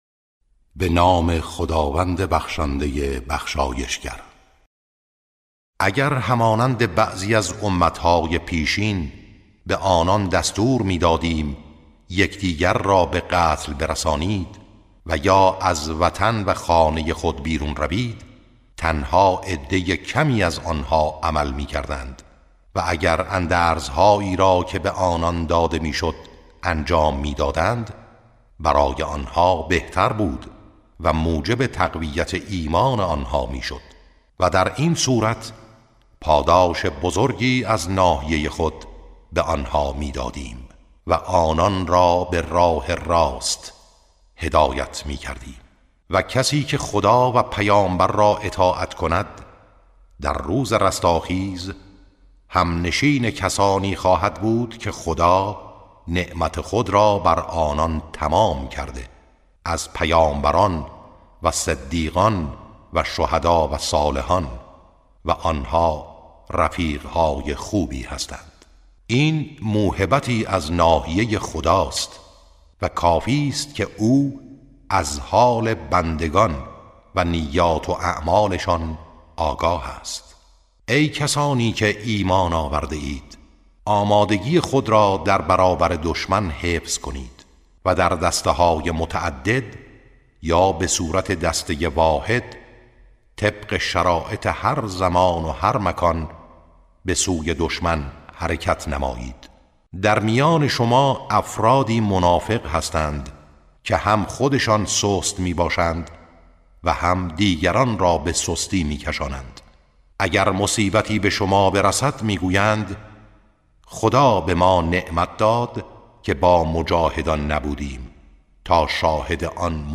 ترتیل صفحه ۸۹ سوره مبارکه نساء(جزء پنجم)